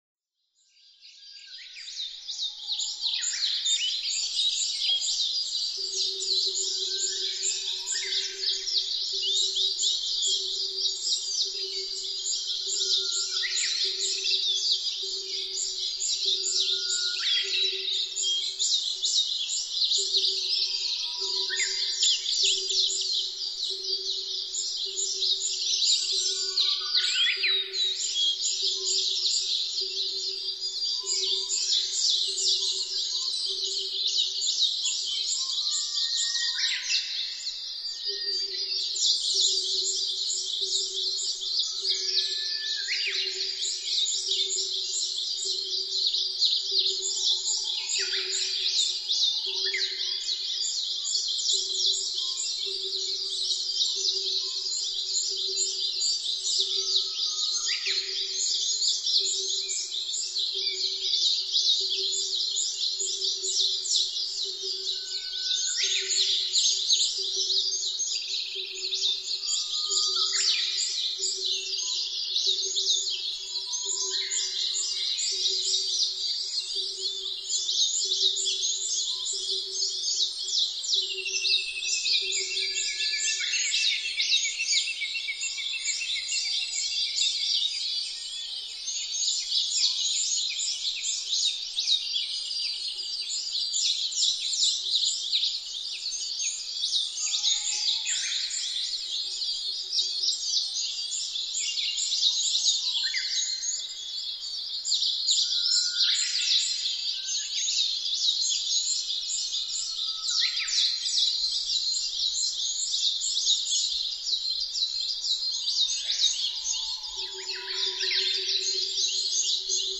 ツツドリ　Cuculus saturatusカッコウ科
日光市湯西川　alt=1170m
Mic: Panasonic WM-61A  Binaural Souce with Dummy Head
囀りピークの時間、ツツドリの声は通奏低音の役割を果たします。
他の自然音：センダイムシクイ、ウグイス、ヒガラ、オオルリ、コノハズク、コマドリ、コルリ、ゴジュウカラ